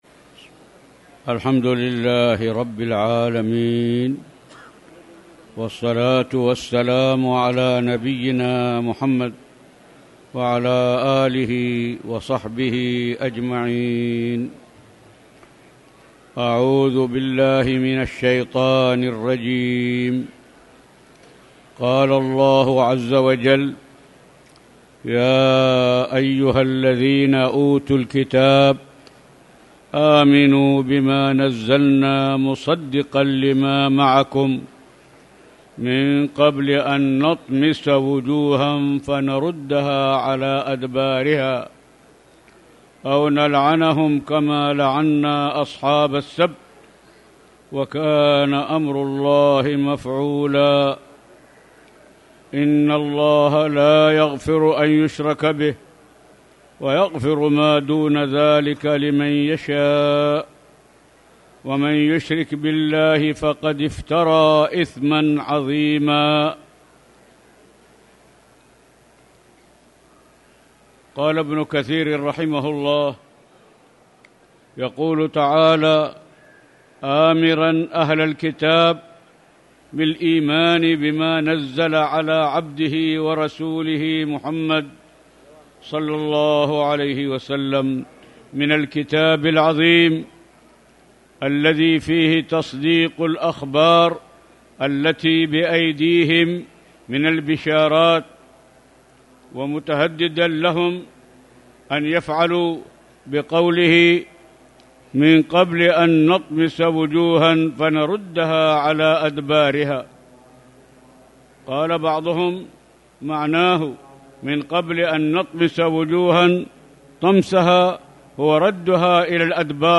تاريخ النشر ٢٨ شوال ١٤٣٨ هـ المكان: المسجد الحرام الشيخ